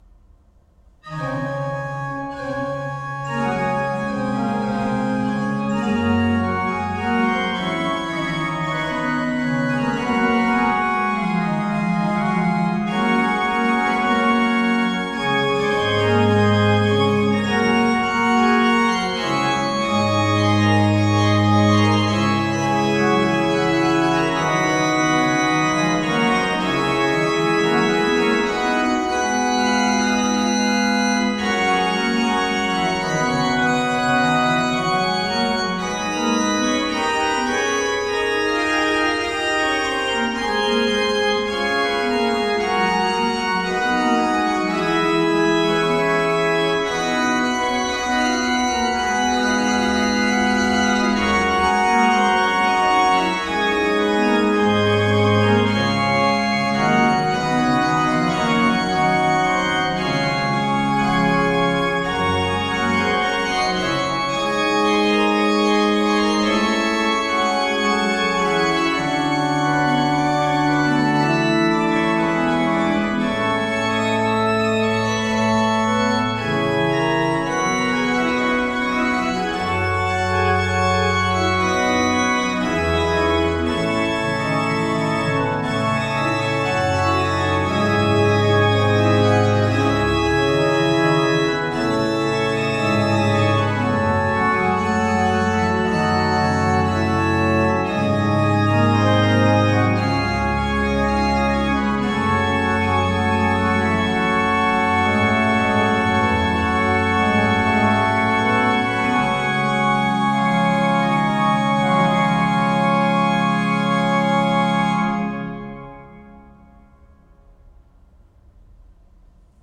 Volume 1 is organ music and vocal music